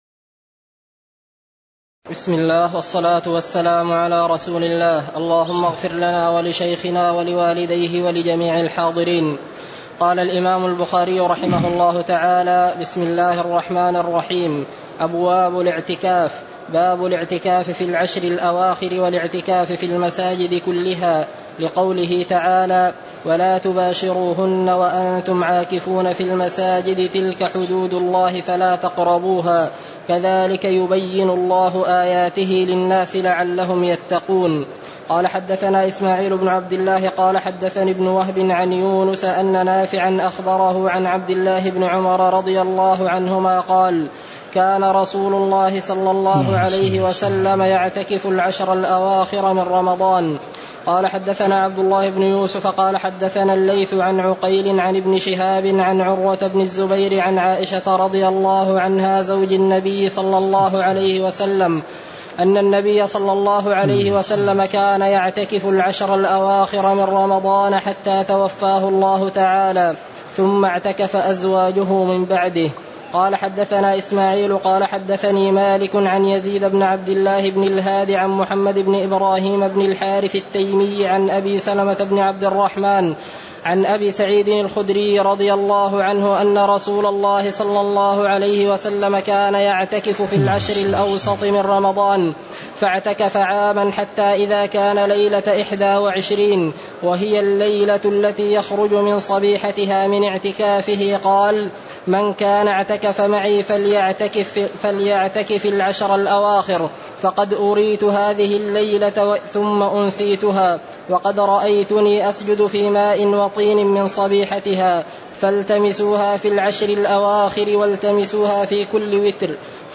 تاريخ النشر ١٨ رمضان ١٤٣٨ هـ المكان: المسجد النبوي الشيخ